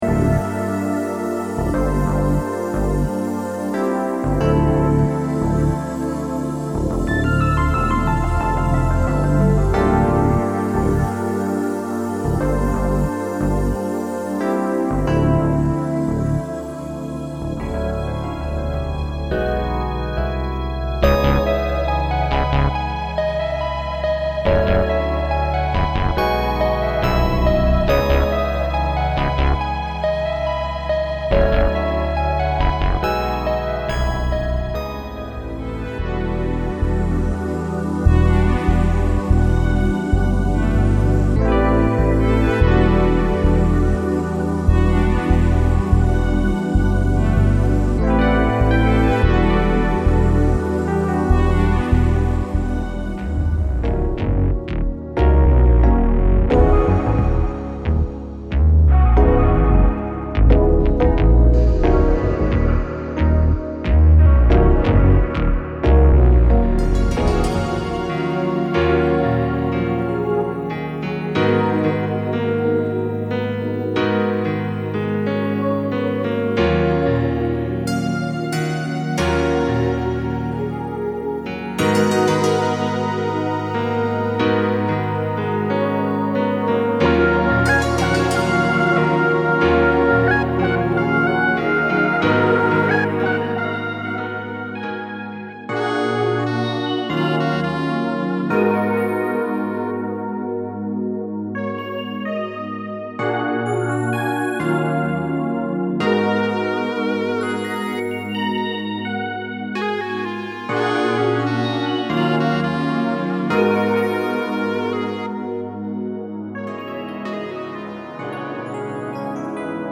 Made with VSTs such as Korg Triton, Xspand, and Omnisphere.